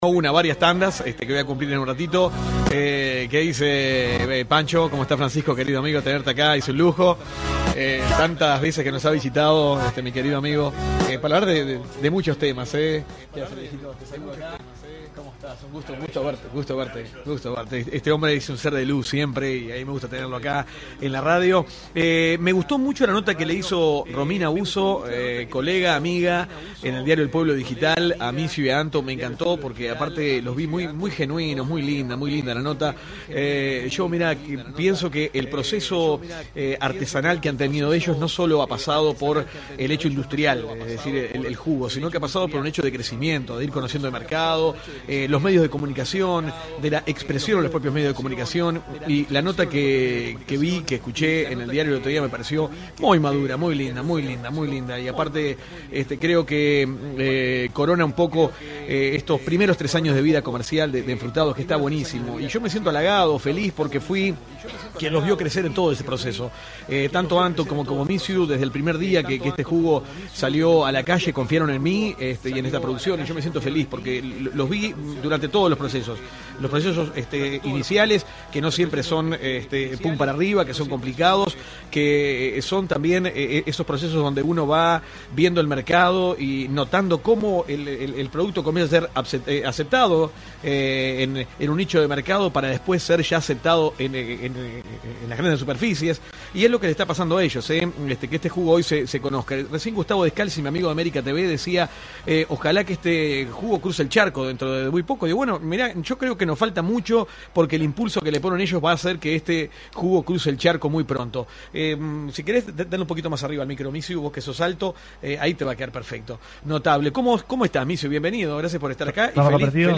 Con su familia acompañándolos tuvimos una amena charla.